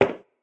inside-step-3.ogg.mp3